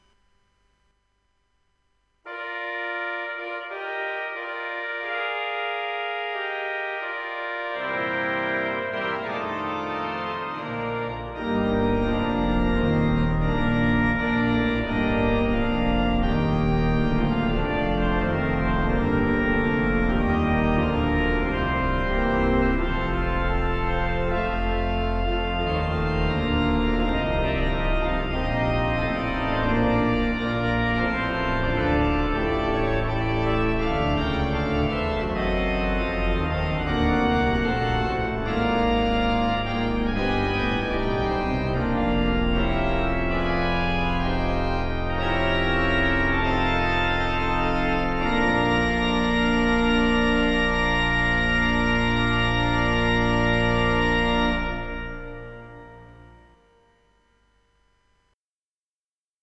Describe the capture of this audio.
Hereford Cathedral Organ (Sample Set)